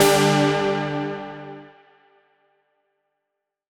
Index of /musicradar/future-rave-samples/Poly Chord Hits/Straight
FR_SARP[hit]-G.wav